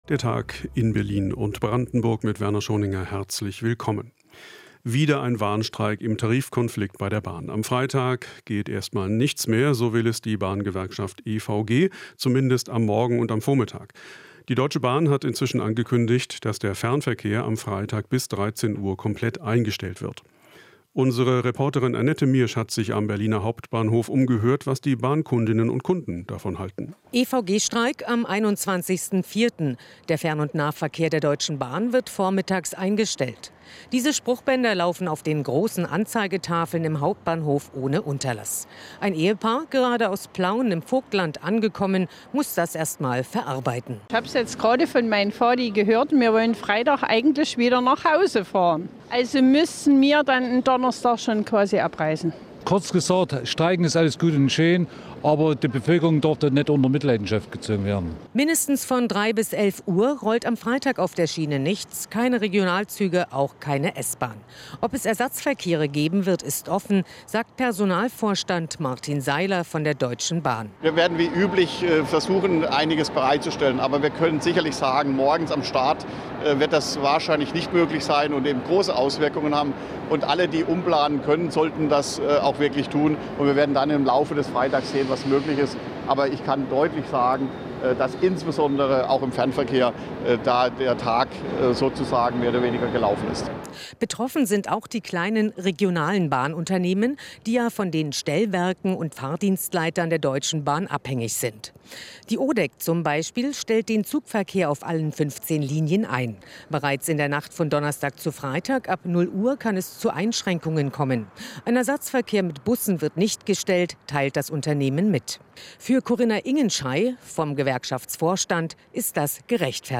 Inforadio Nachrichten, 19.04.2023, 19:00 Uhr - 19.04.2023